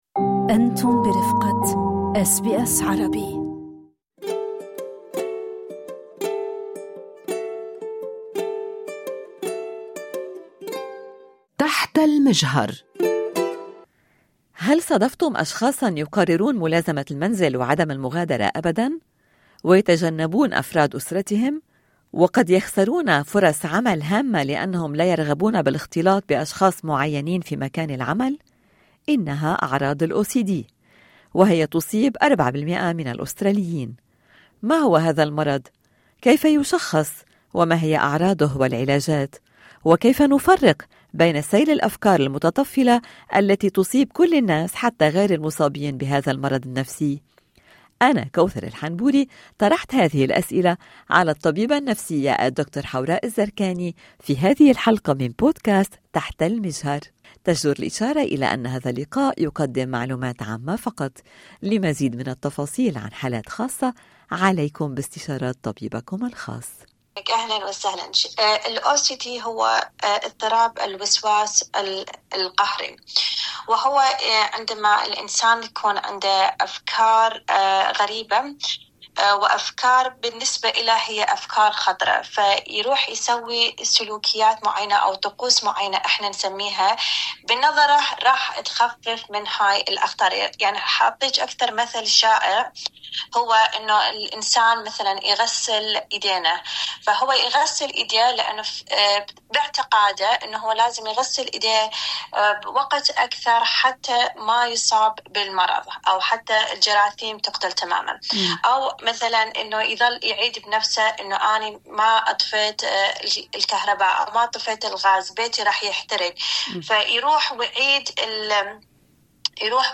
التفاصيل ضمن المقابلة في الملف الصوتي أعلاه أكملوا الحوار على حساباتنا على فيسبوك و انستغرام.